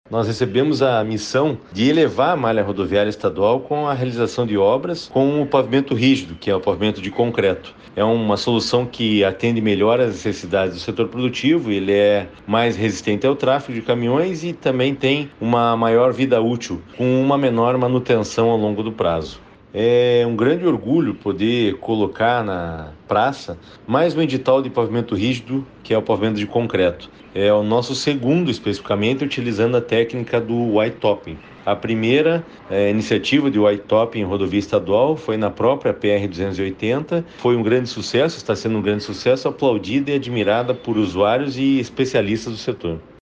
Sonora do secretário de Infraestrutura e Logística, Fernando Furiatti, sobre a nova obra de restauração em concreto da PRC-280